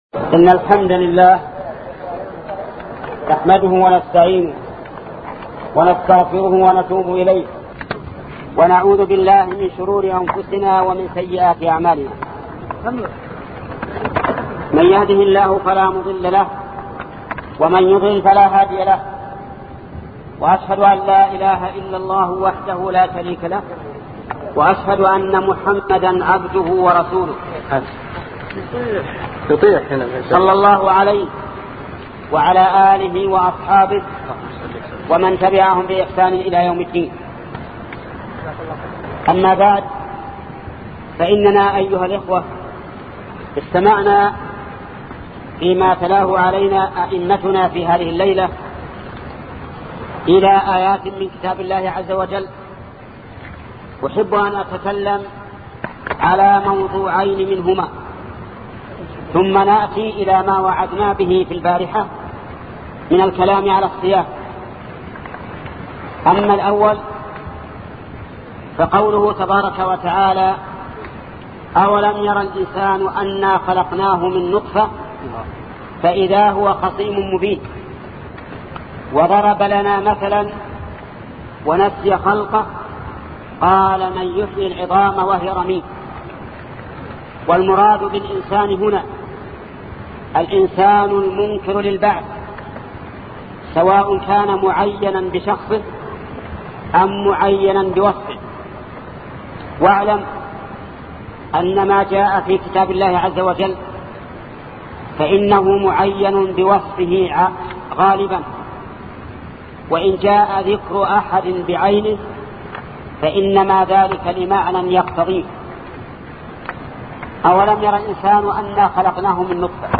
شبكة المعرفة الإسلامية | الدروس | فتاوئ الحرم المكي 1407هـ 04 |محمد بن صالح العثيمين
فتاوئ الحرم المكي 1407هـ 04